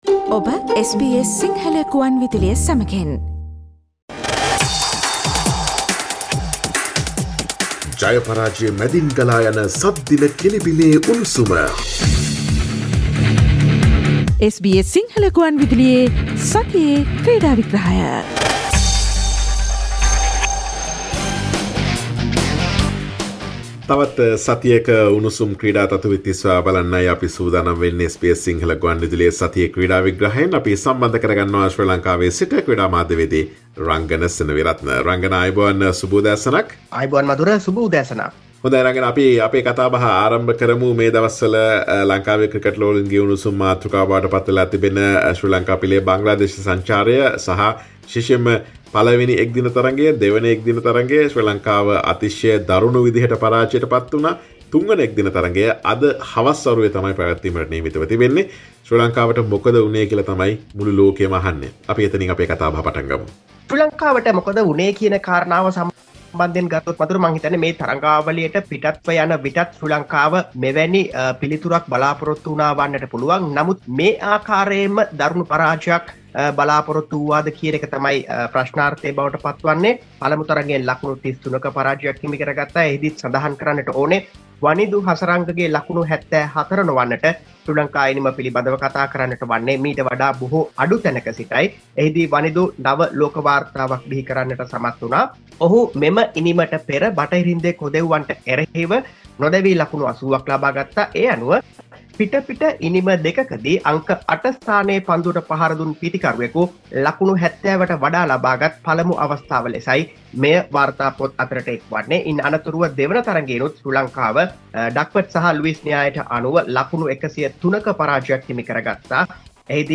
Sports Journalist